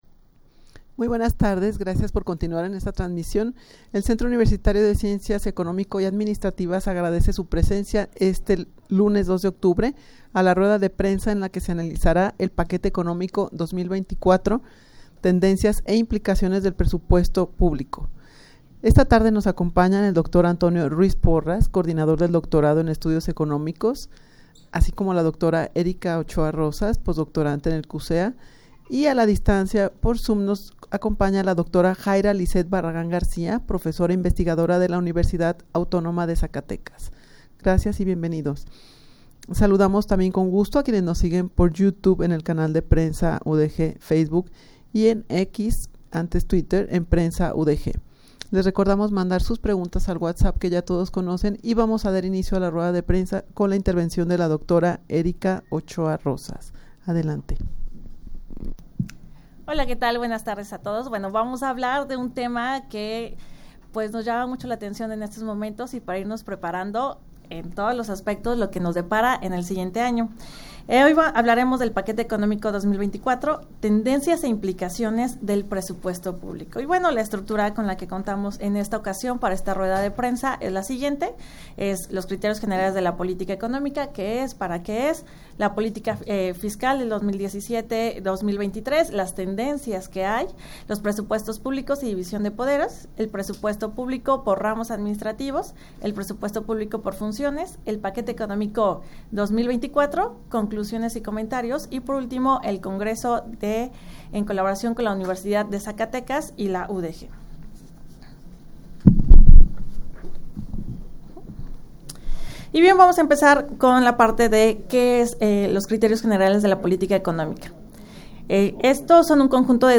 Audio de la Rueda de Prensa
rueda-de-prensa-en-la-que-se-analizara-el-paquete-economico-2024-tendencias-e-implicaciones-del-presupuesto-publico.mp3